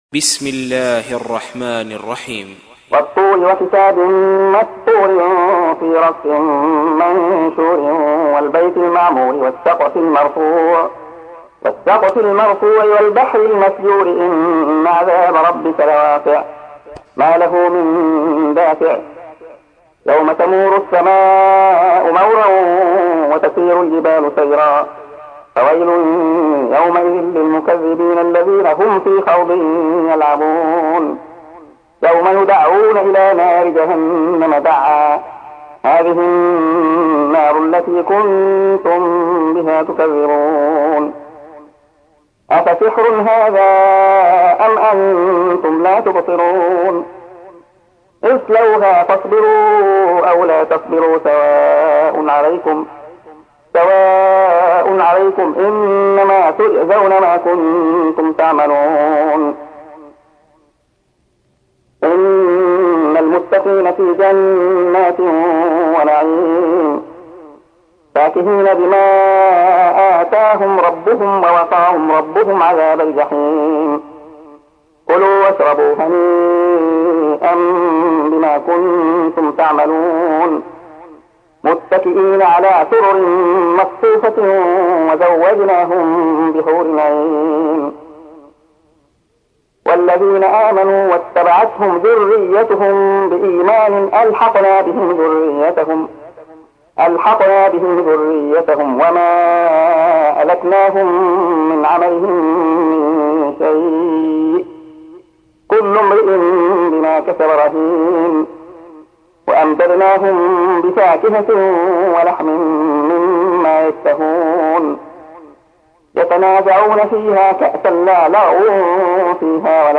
تحميل : 52. سورة الطور / القارئ عبد الله خياط / القرآن الكريم / موقع يا حسين